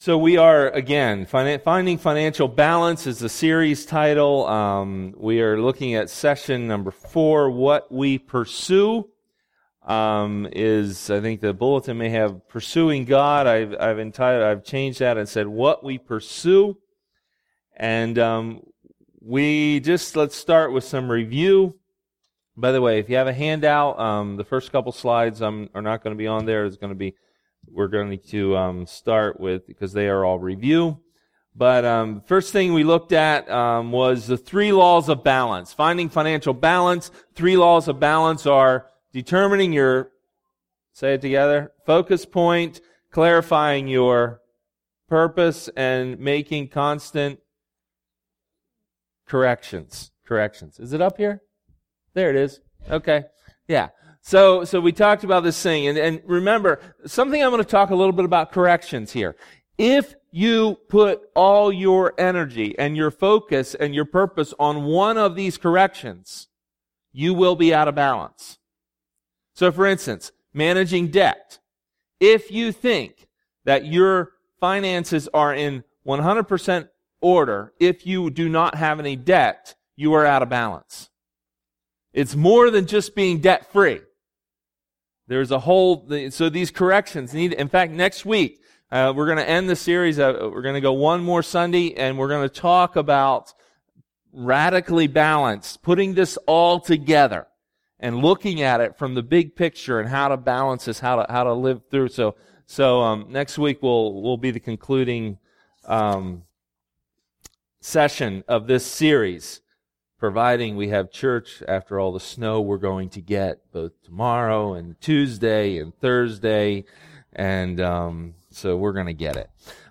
Maranatha Fellowship's Sunday Morning sermon recordings.
Sunday Sermons